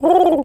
pigeon_call_calm_04.wav